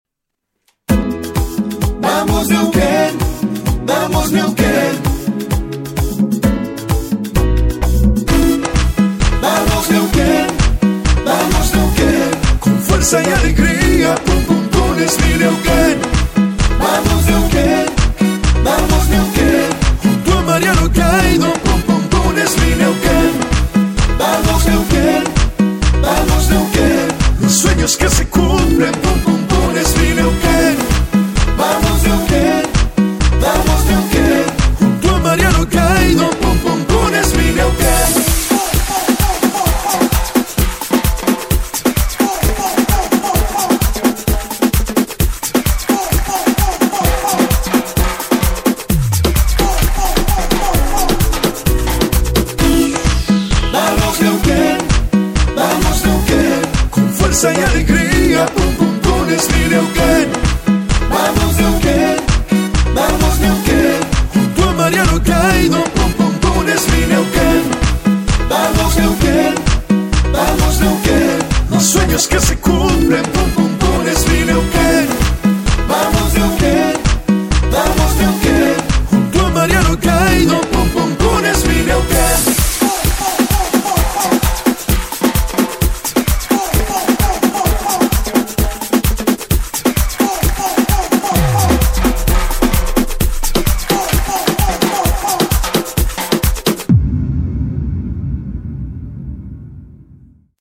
en igual ritmo de merengue